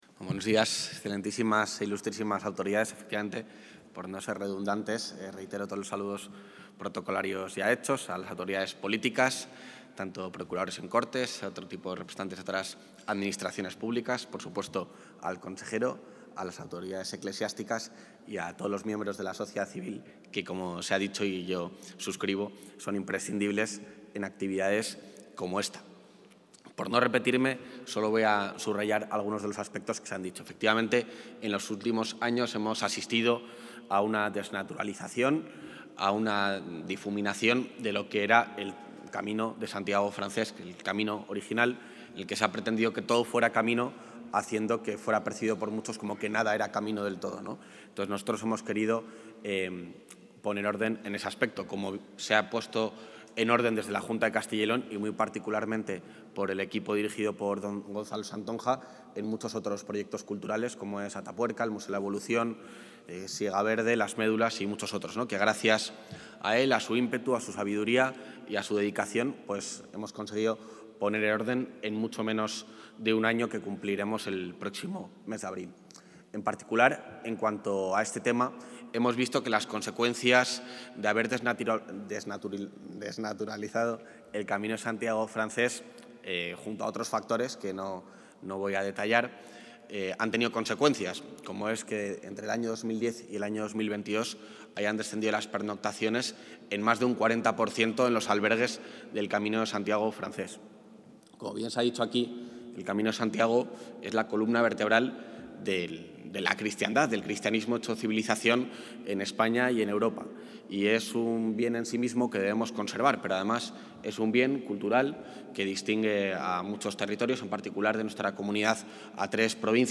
Intervención del vicepresidente.
El vicepresidente de la Junta de Castilla y León, Juan García-Gallardo, ha presidido durante la mañana de hoy en la Capilla de los Condestables de la Catedral de Burgos el acto de presentación del Plan Director del Camino de Santiago Francés y la firma del convenio entre la Consejería de Cultura, Turismo y Deporte y Camino Francés Federación (CFF), una entidad que aglutina a 18 asociaciones agrupadas y más de 5.000 socios.